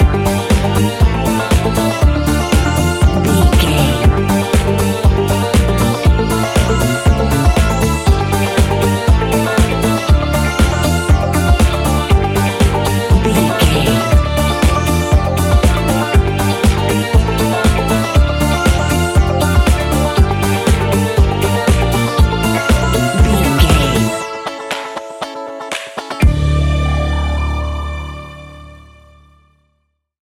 Ionian/Major
D
house
electro dance
synths
techno
trance